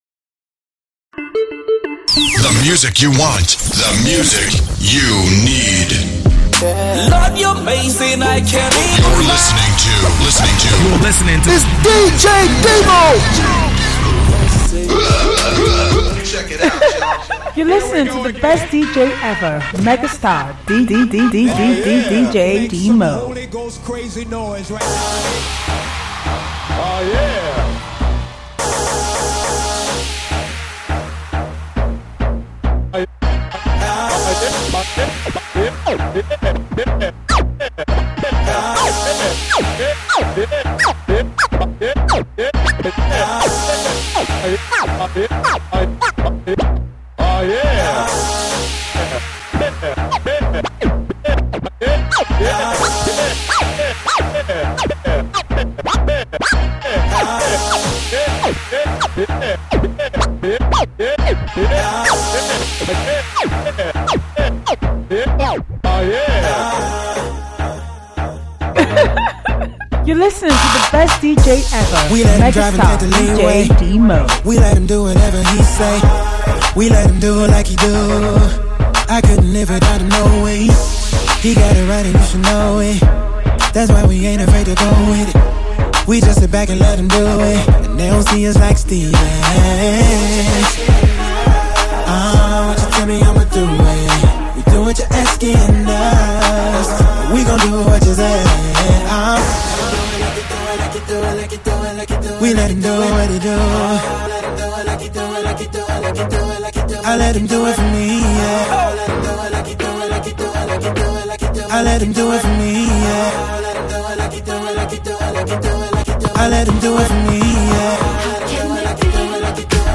mid-tempo African and western songs
back to back gospel bliss